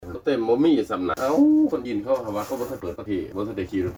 ສໍາພາດ 3 (ພະນັກງານຂັບລົດເມລັດວິສາຫະກິດ)